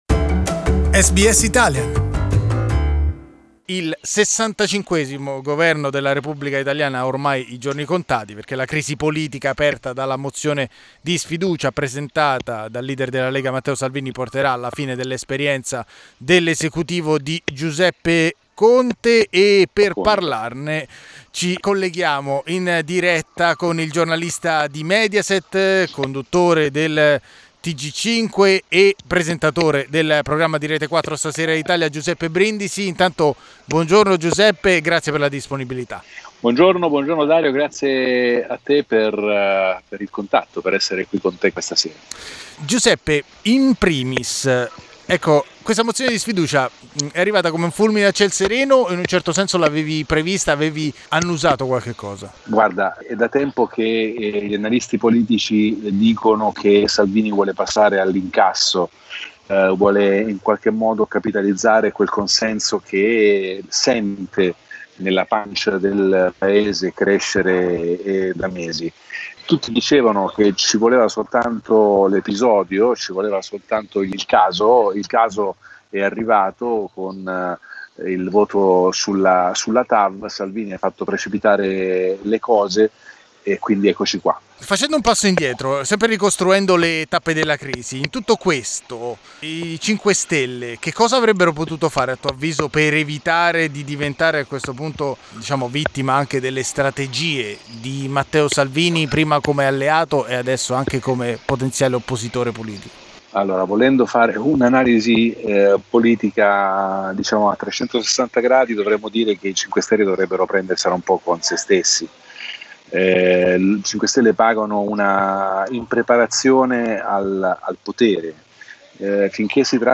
L'analisi del giornalista di Mediaset Giuseppe Brindisi.